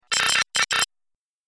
دانلود صدای ربات 12 از ساعد نیوز با لینک مستقیم و کیفیت بالا
جلوه های صوتی